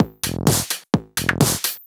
Electrohouse Loop 128 BPM (37).wav